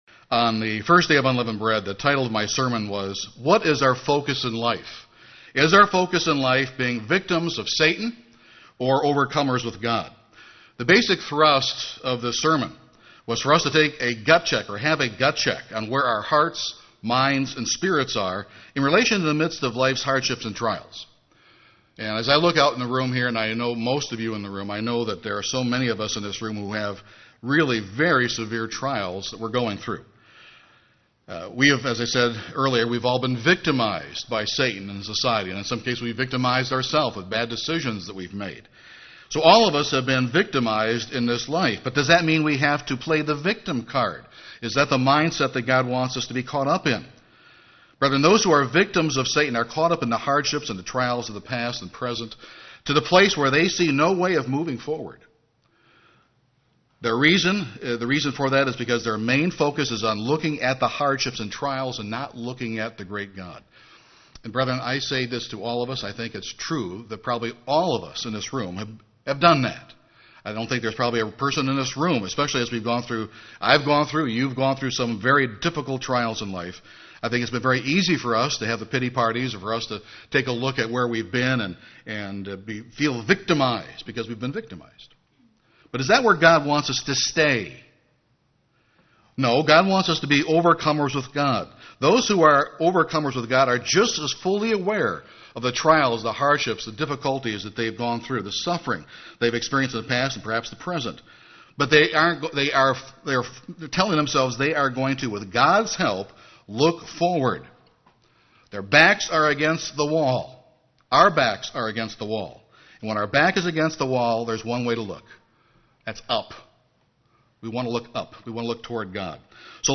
This sermon will explore what God wants us to do when we feel that our back is against the wall.